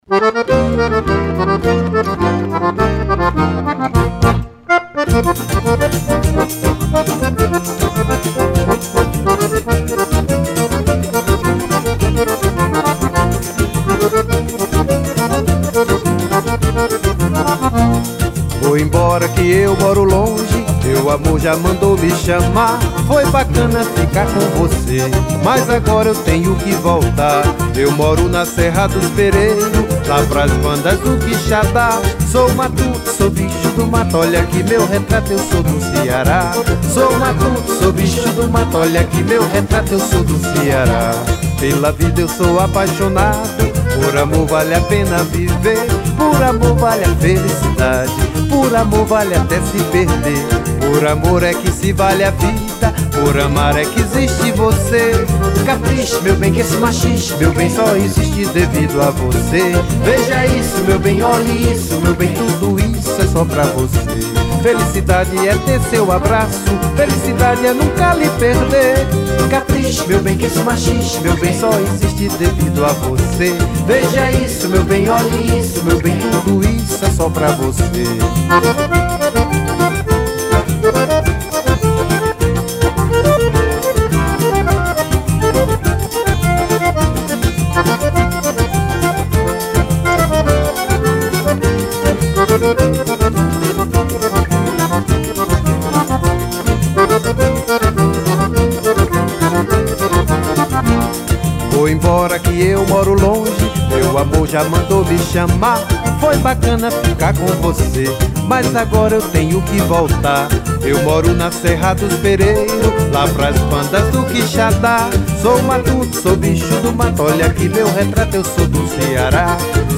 1450   02:56:00   Faixa:     Forró